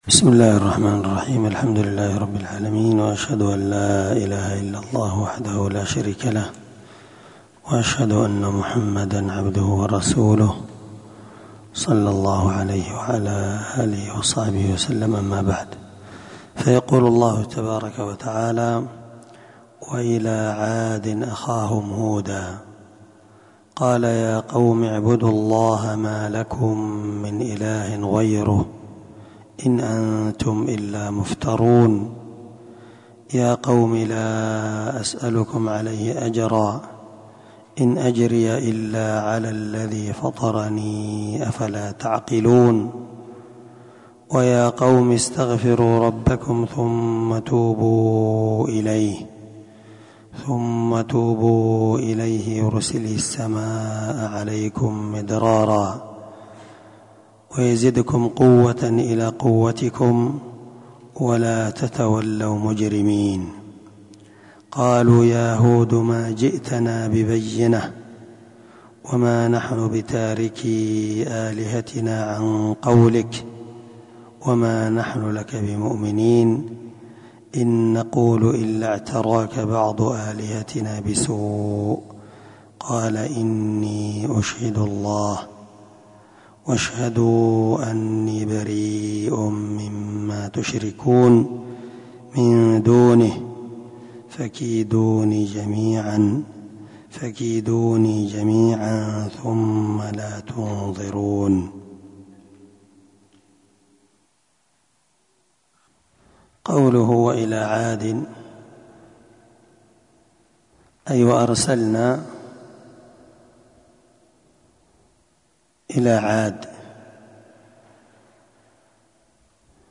634الدرس 15تفسير آية ( 50- 55) من سورة هود من تفسير القرآن الكريم مع قراءة لتفسير السعدي
دار الحديث- المَحاوِلة- الصبيحة.